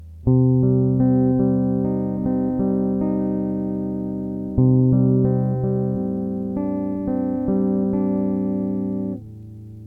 Nápověda: metalová skupina na "K", písnička o lásce.